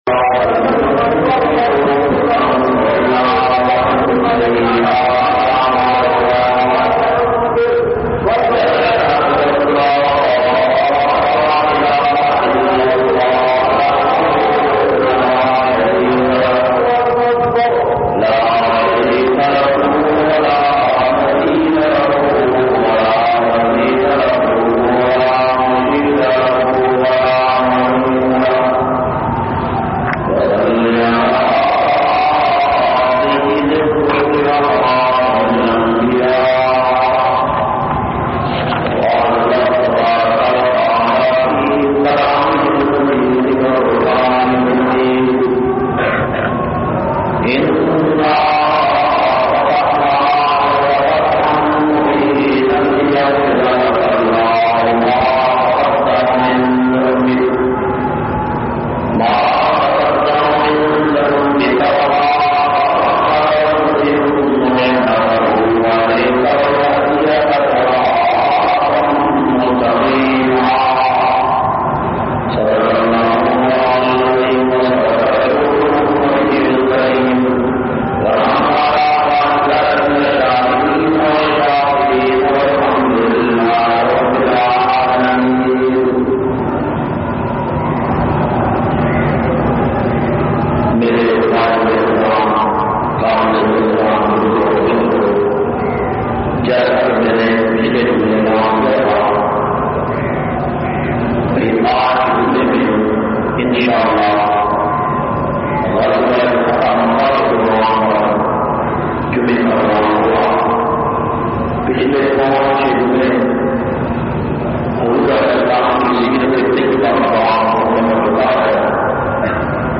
455- Fatah Makkah Jumma khutba Jamia Masjid Muhammadia Samandri Faisalabad.mp3